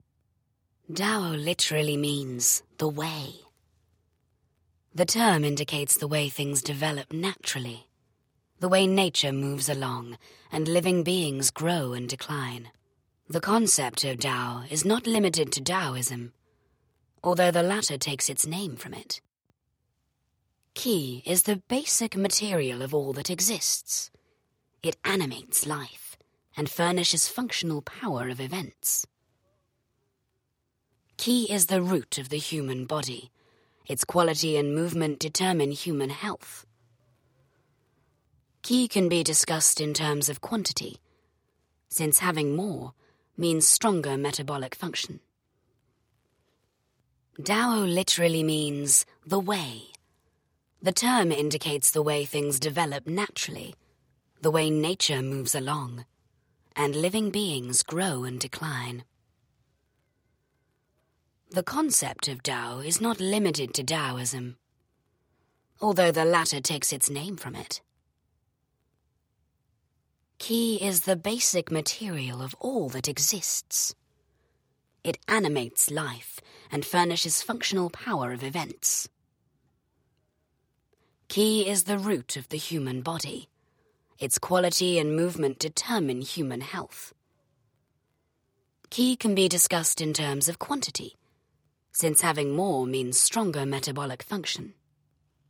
Female
English (British)
Husky and warm RP voice, with a natural sexy rasp. Sophisticated and smooth.
A husky and warm British voice of authority, clarity and confidence.
Narration